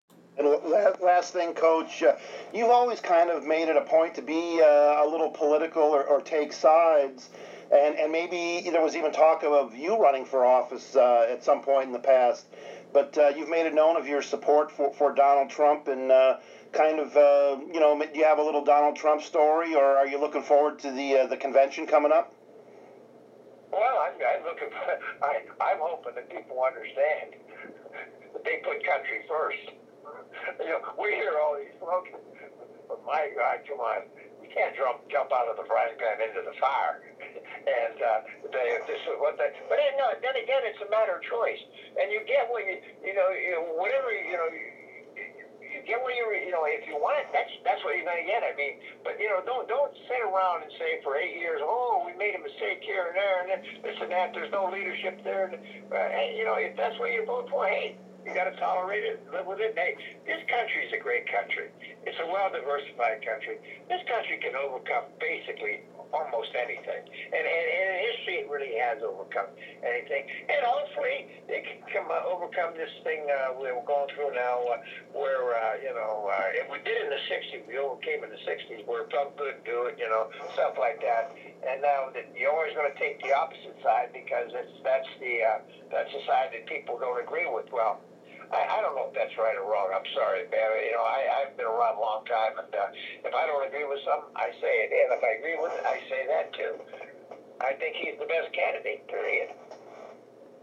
interviews Coach Mike Ditka and he talks a little about endorsing Trump